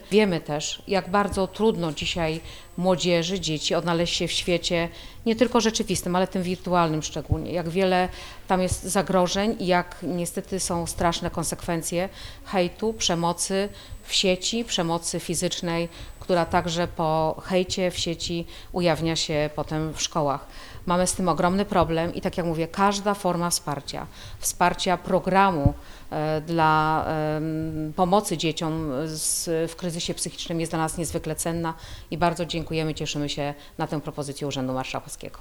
Ewa Skrzywanek – Dolnośląska Kurator Oświaty zaznacza, że każda forma wsparcia zdrowia psychicznego dzieci i młodzieży jest bardzo ważna.